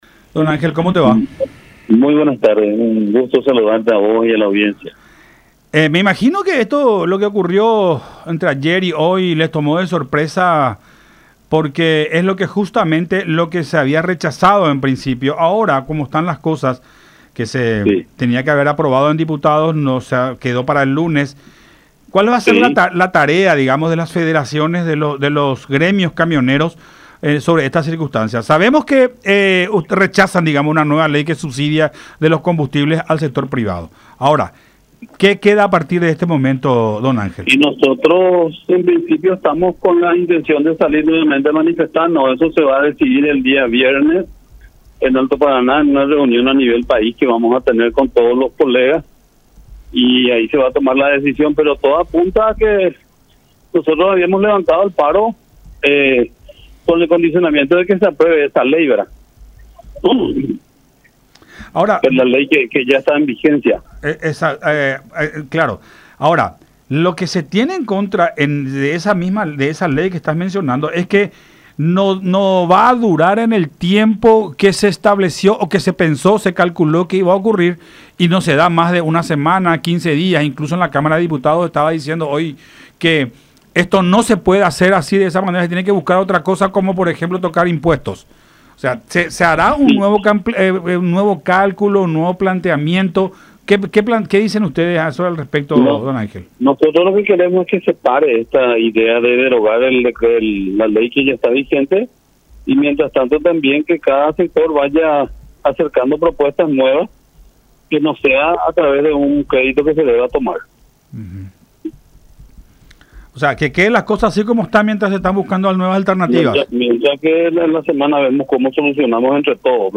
en conversación con Buenas Tardes La Unión